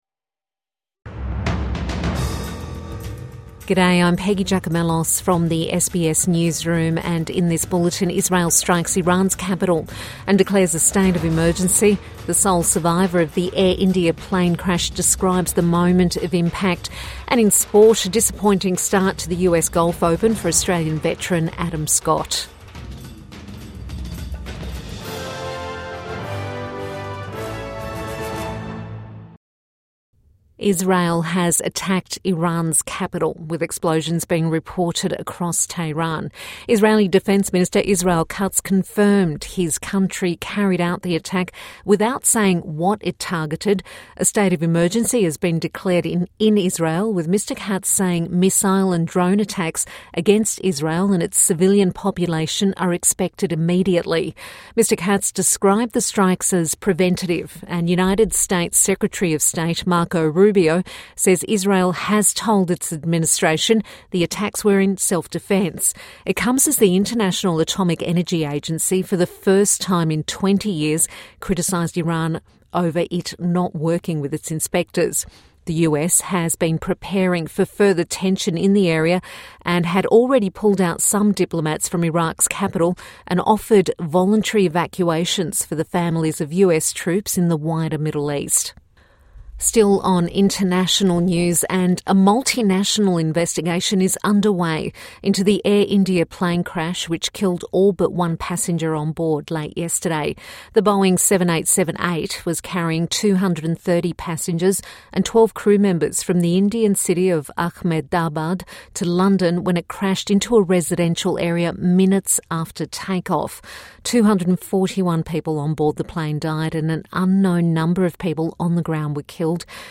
Israel strikes Iran's capital | Midday News Bulletin 13 June 2025